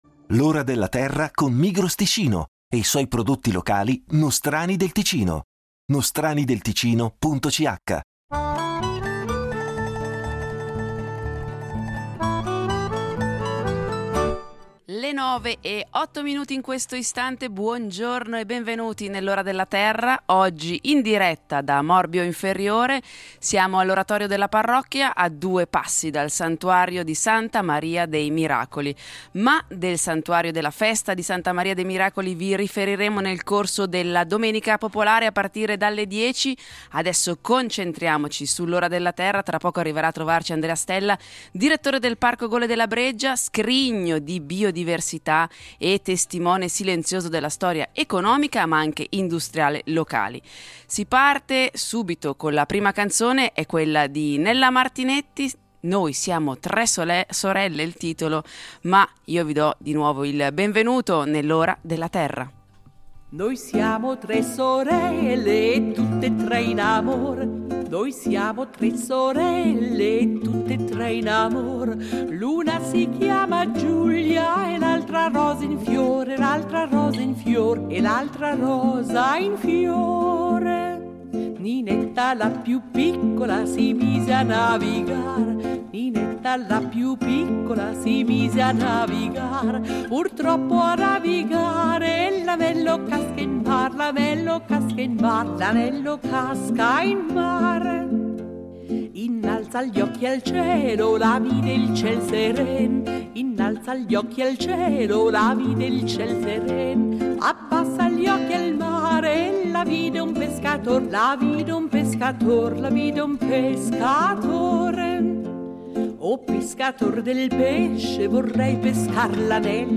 In diretta da Morbio inferiore vi racconteremo del Parco Gole della Breggia . Il torrente Breggia scopre, in solo un chilometro e mezzo, rocce che aprono una finestra unica sul passato della Terra.